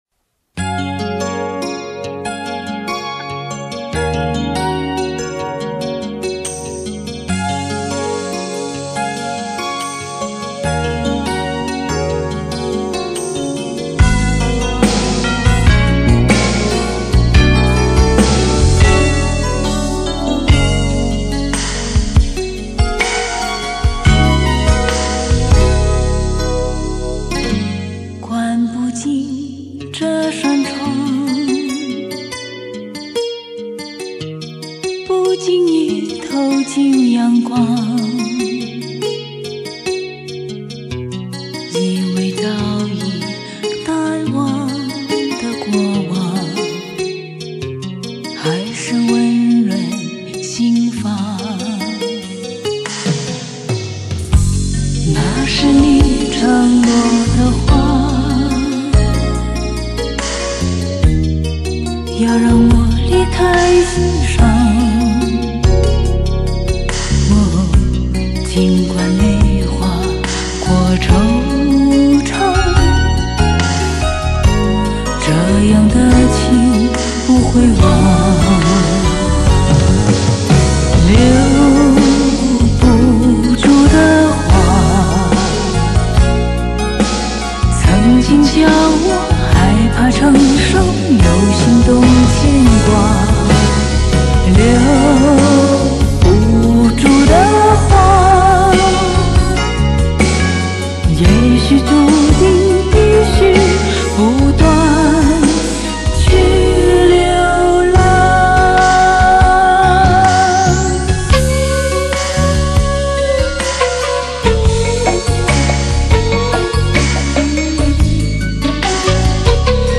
异国情调的曲风
华丽的配乐
（电子编钟+电子木笛）
优雅 浪漫 唯美 感性
中间一段电子木笛SOLO
令人无限放松和陶醉